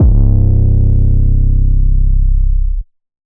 Drums Archives - AngelicVibes
808_Oneshot_Flame_C.wav